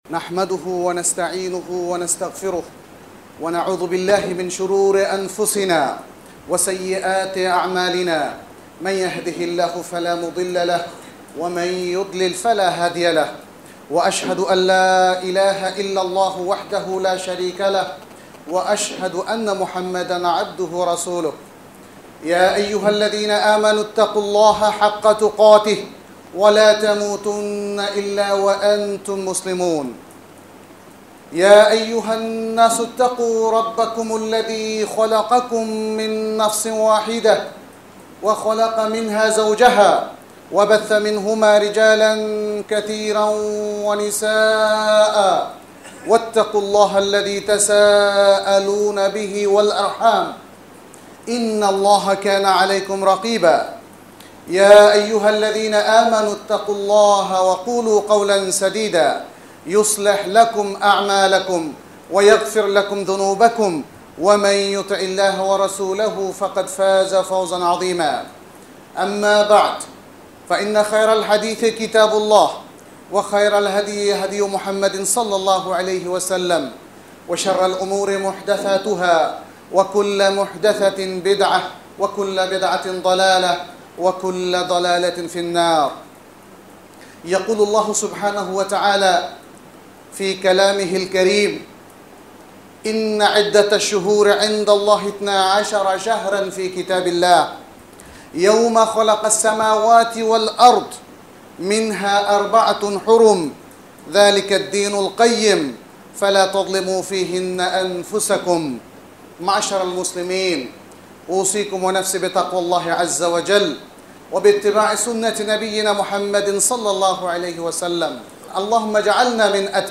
محاضرة صوتية باللغة البنغالية، وفيها بيان أهمية الحج في حياة المسلم، وأنه ركن من أركان الإسلام، وأن الحج مدرسة لتحقيق التوحيد، وإقامة العبودية لله - سبحانه وتعالى -، مع بيان بعض المسائل التي يجب أن نتعلمها؛ كي نحسن أداء المناسك كما أداها رسول الله - صلى الله عليه وسلم -.